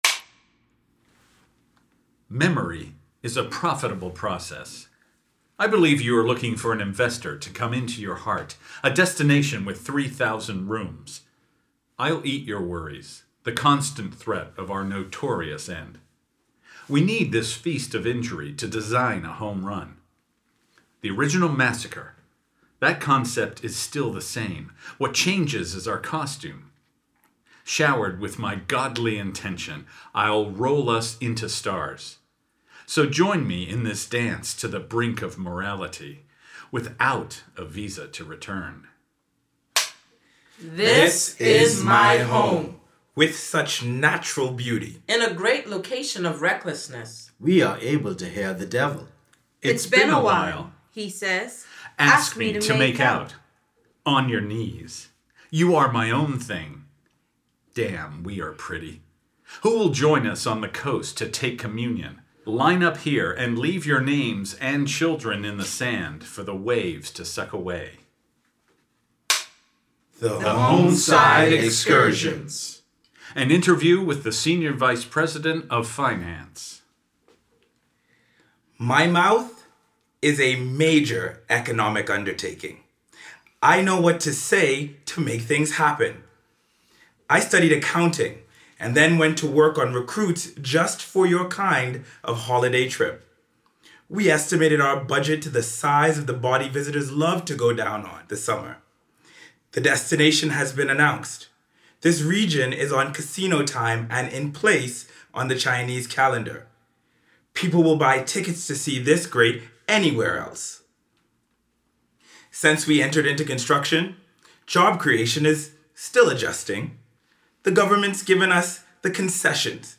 Morphing into different roles and taking on the range of voices drawn from the eighteen poetic interviews in the collection, the performers emphasized the insidious fluidity that the hospitality industry demands from its workers.